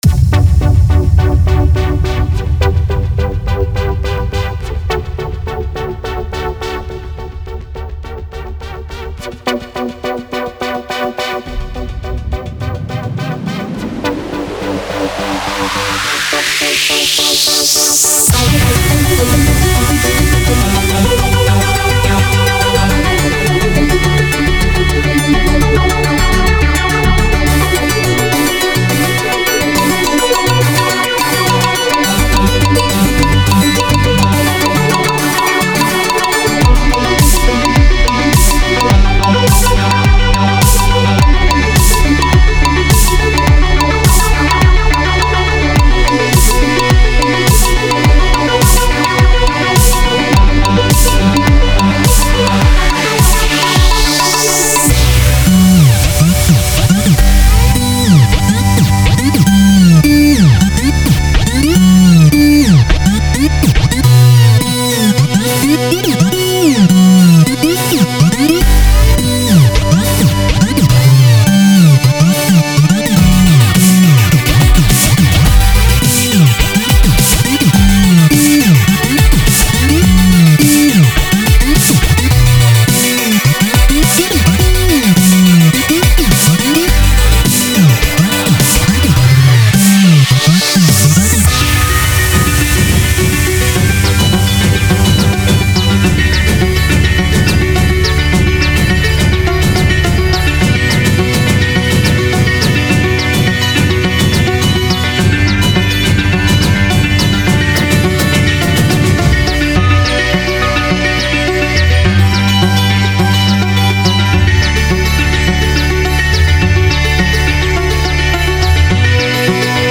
Genre : Electro